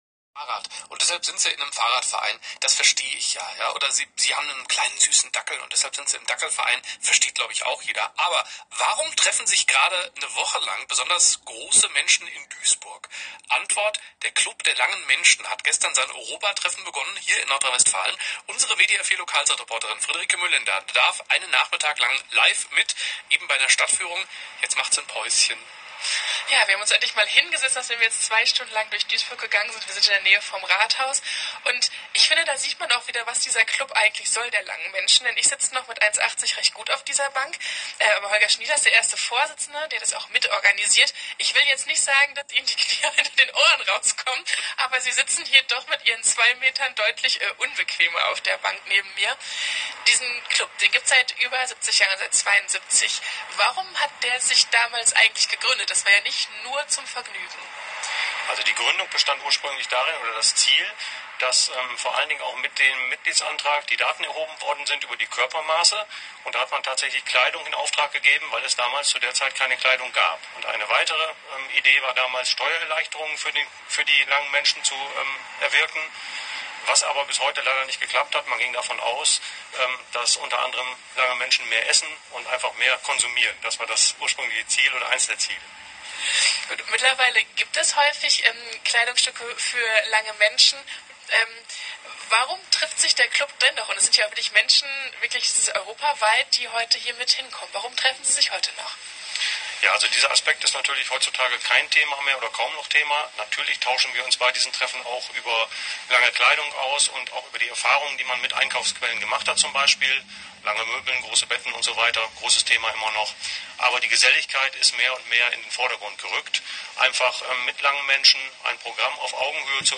26.05.2025 WDR4 radio report interview 1st Chairman